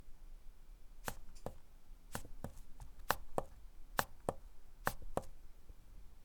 • The big downside of the PED 500 is that it makes a slight noise when pressed. In fact, it’s a double noise: the thud of the switch itself, and the sound of the air being expelled (“ffffff”) that passes between the pedal body and the rubber cover. It’s not very loud and it mainly affects classical musicians, but it’s a bummer!
AirTurn-PED-500-Pedal-Switch-noise.mp3